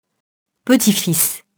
petit-fils [pətifis] nom masculin (pluriel petits-fils)